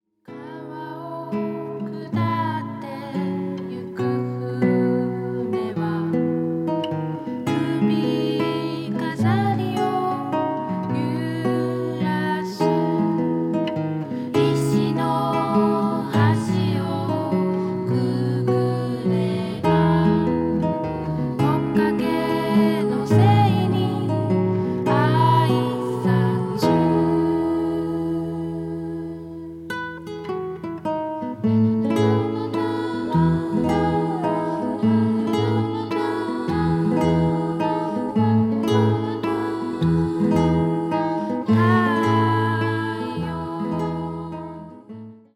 幻想の中に真理がある、童話のような歌たち。
サイケデリックであるけれど、アングラ過ぎない。